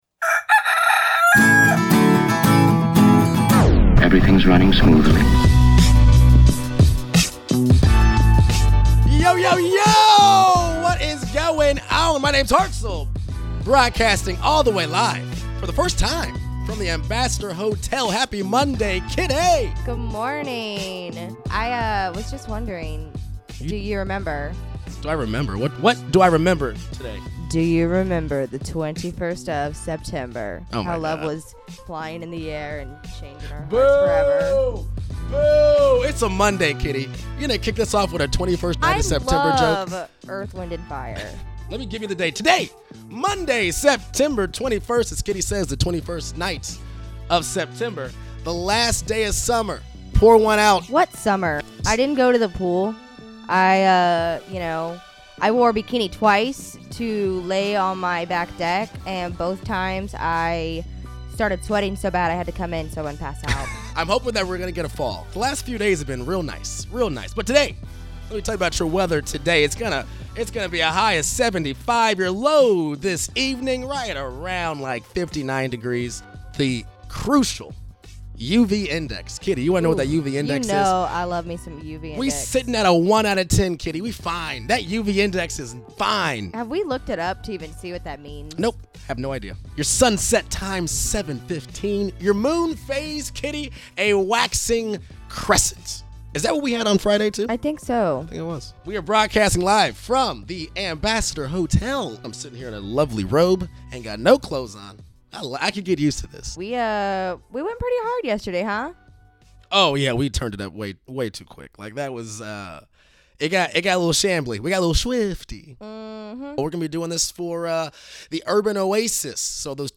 Happy Monday from The KC Morning Show. We're LIVE this morning from the Ambassador Hotel!